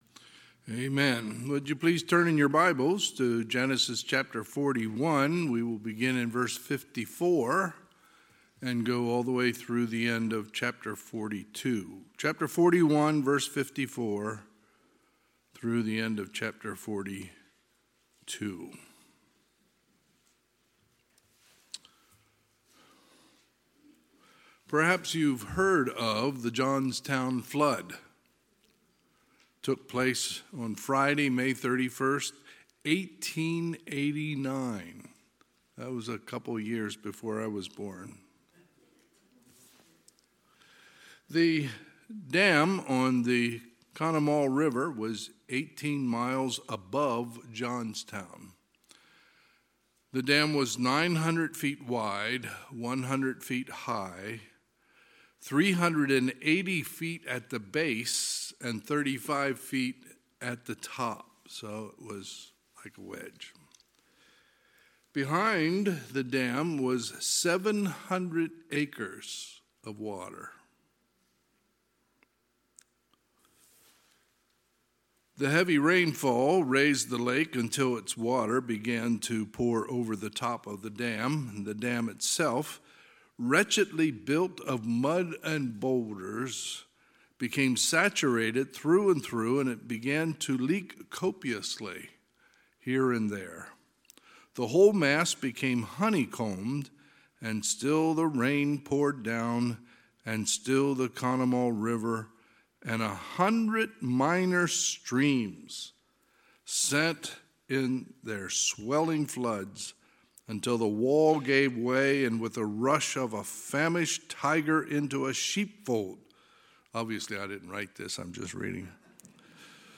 Sunday, February 26, 2023 – Sunday PM
2023 Sermons admin Genesis 41:54